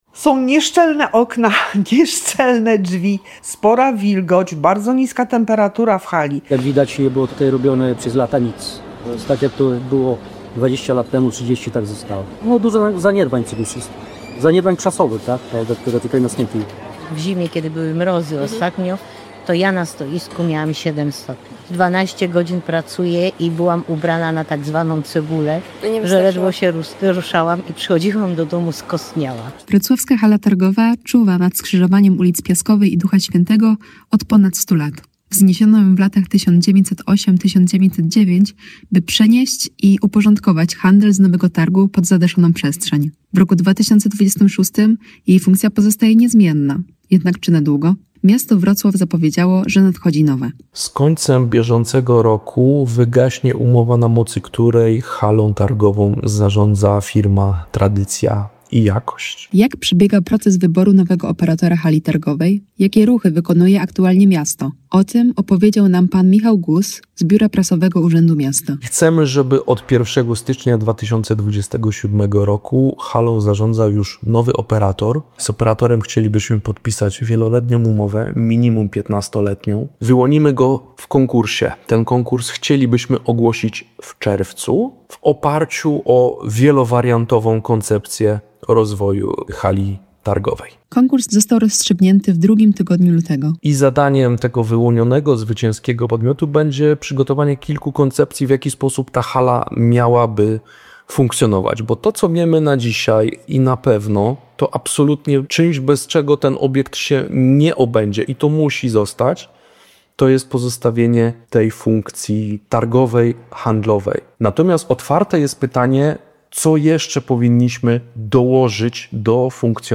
reportaż audio